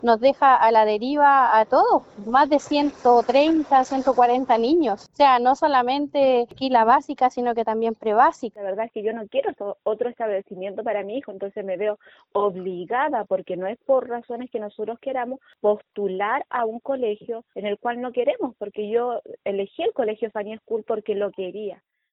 Los apoderados afirmaron estar a la deriva y lamentaron verse obligados a tener que cambiar a sus hijos de colegio.
apoderados-colegio-funny-school.mp3